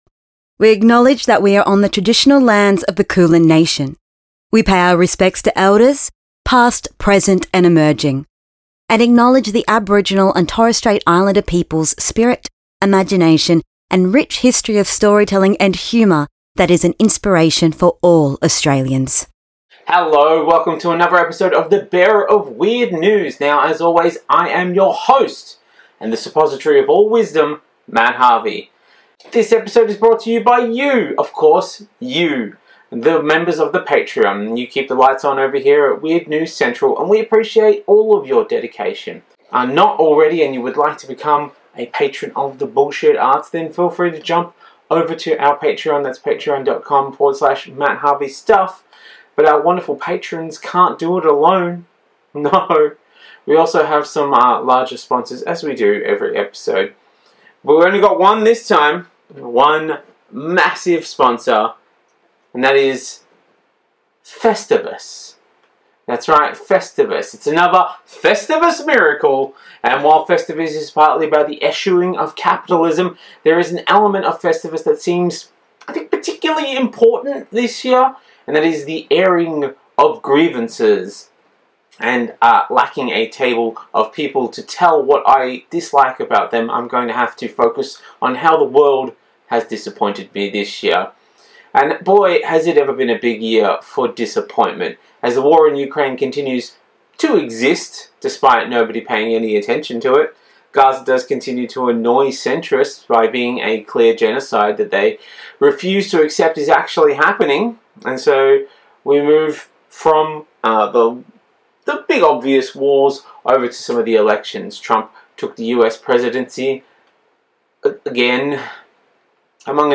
Australian news round-up